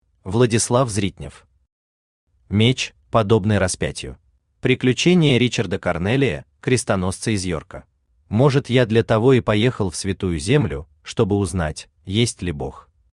Аудиокнига Меч, подобный распятью | Библиотека аудиокниг
Aудиокнига Меч, подобный распятью Автор Владислав Зритнев Читает аудиокнигу Авточтец ЛитРес.